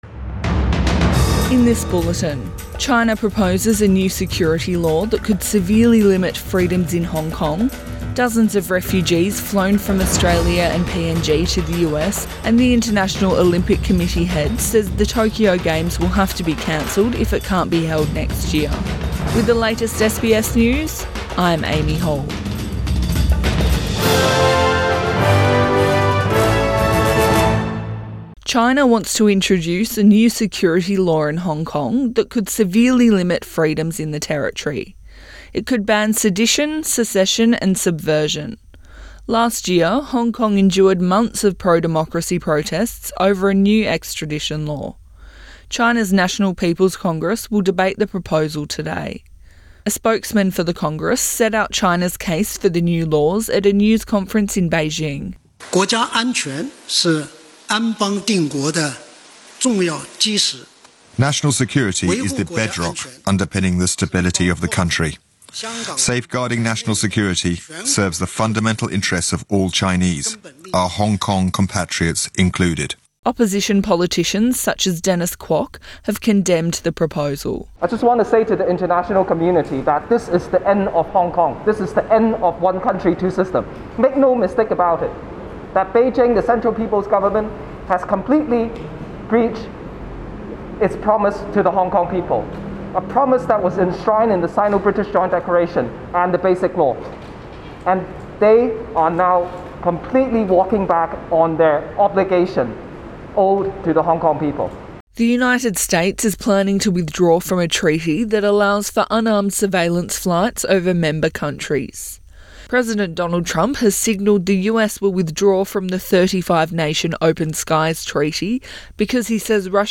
AM bulletin 22 May 2020